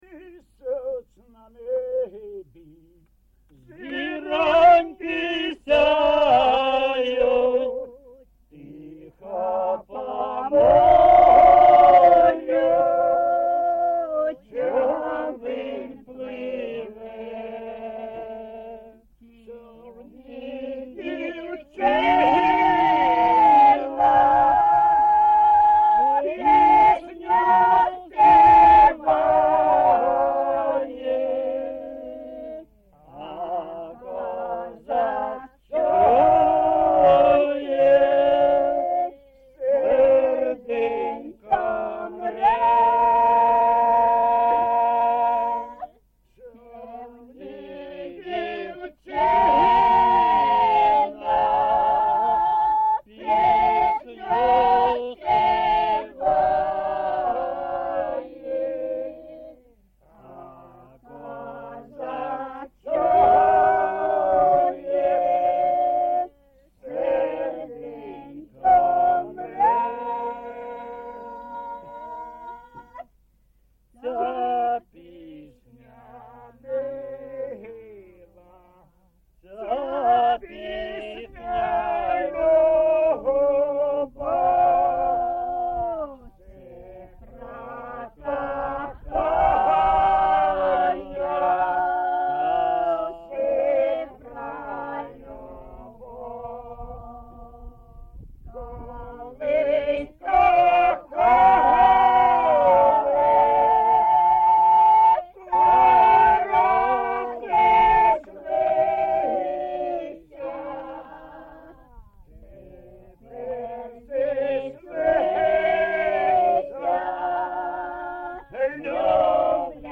ЖанрРоманси, Пісні літературного походження
Місце записум. Дружківка, Краматорський район, Донецька обл., Україна, Слобожанщина